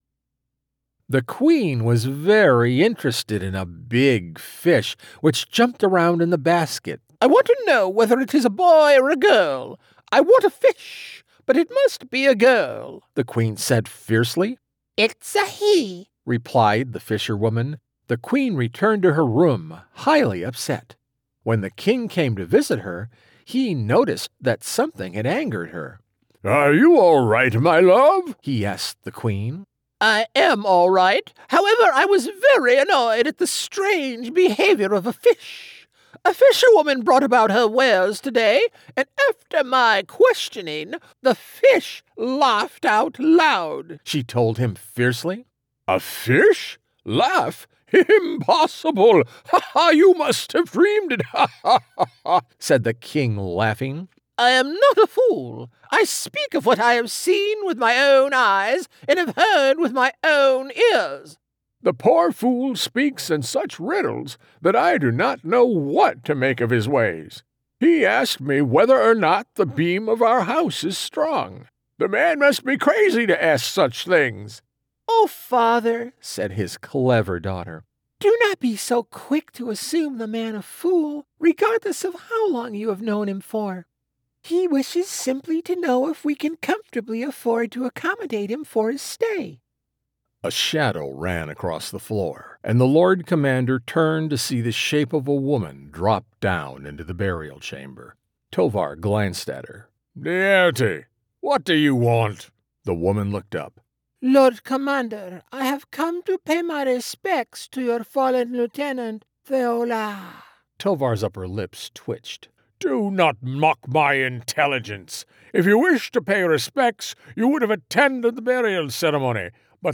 Audiobook Demo